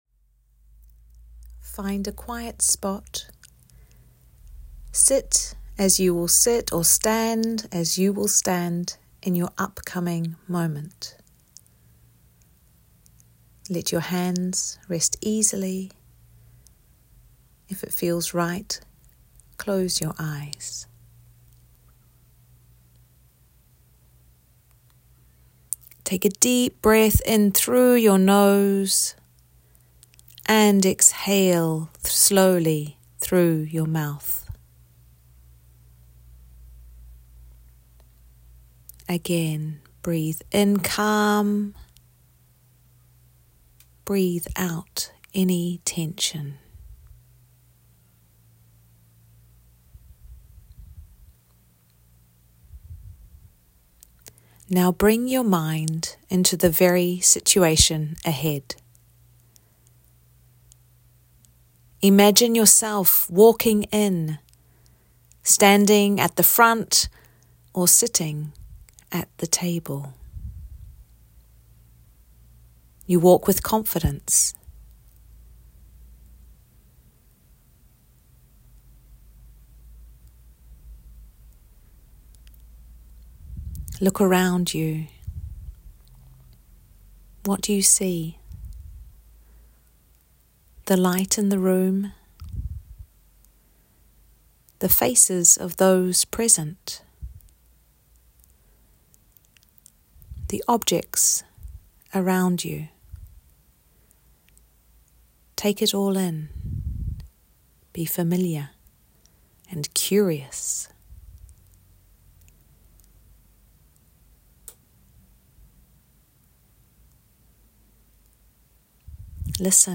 • Guided audio practices to calm your body and steady your mind
A 6-minute guided visualisation to mentally rehearse your upcoming courageous action.